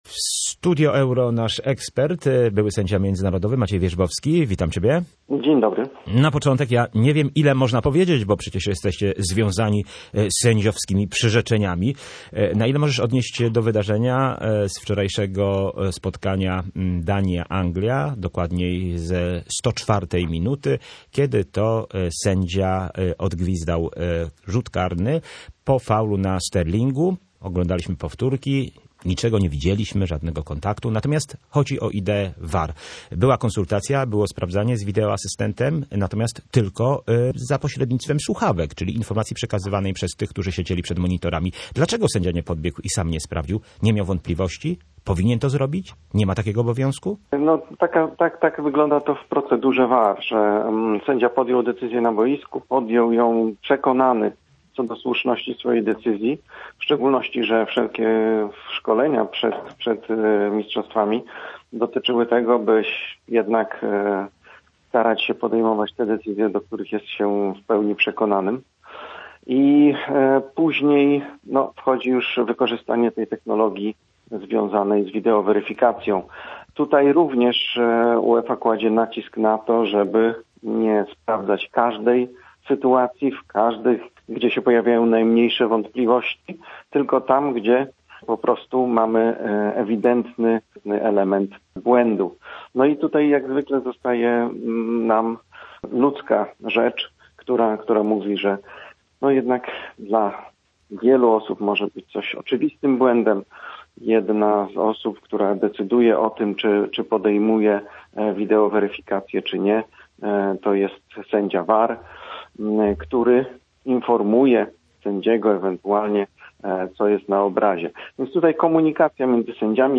VAR wybudził Duńczyków ze snu o mistrzostwie Europy. Ekspert komentuje decyzję